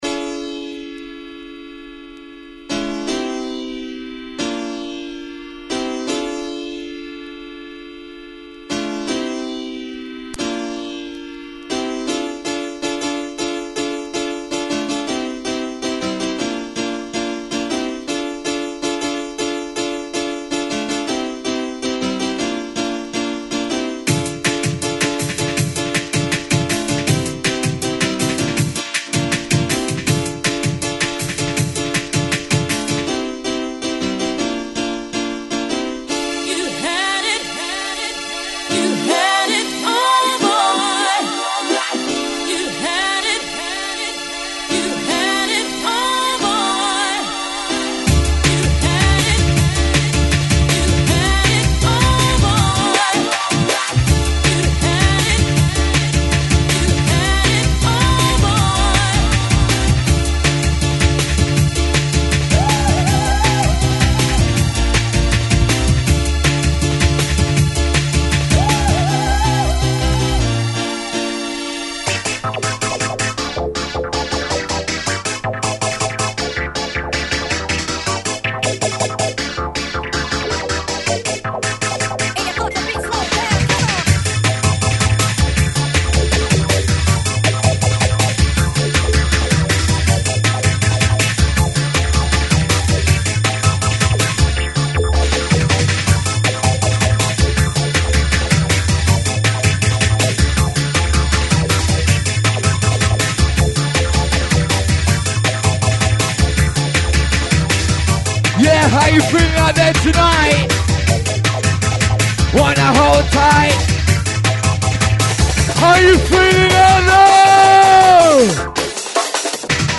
Old Skool Drum & Bass Set Live Recording
Oldskool Drum 'n Bass & Breakbeat